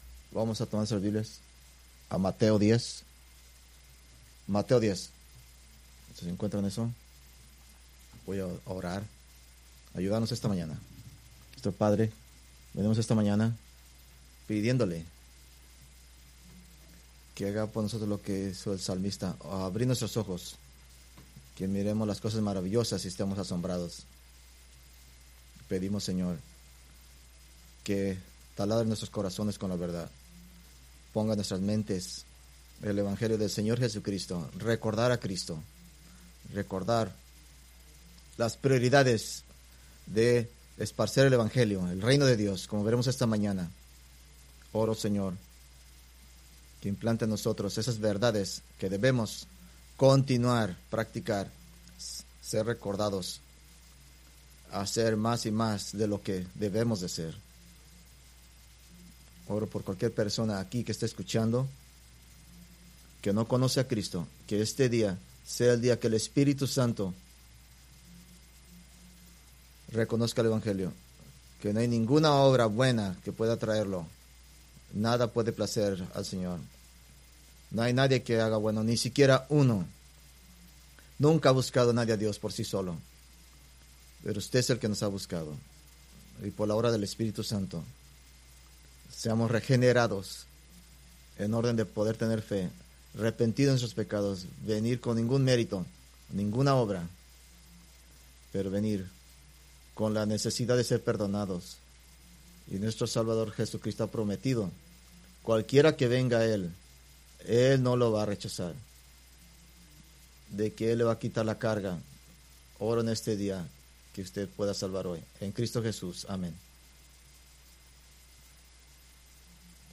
Preached February 23, 2025 from Mateo 10:3